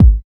90 KICK.wav